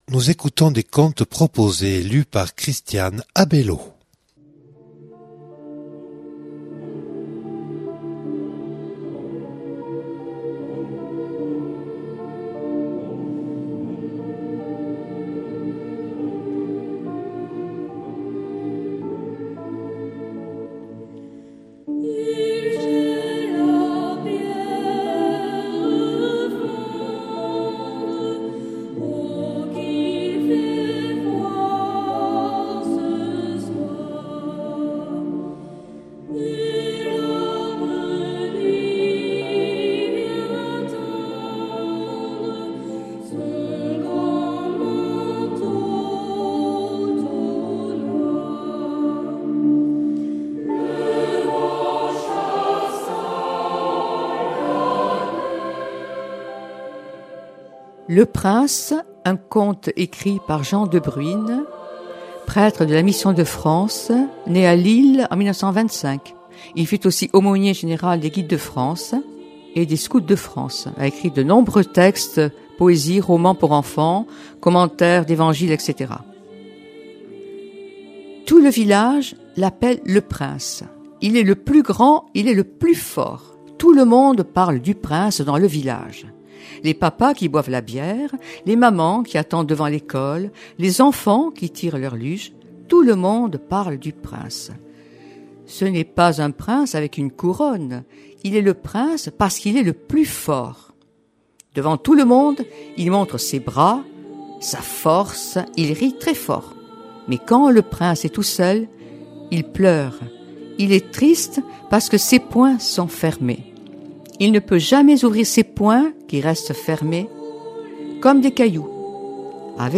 mardi 1er janvier 2019 Contes de Noël Durée 8 min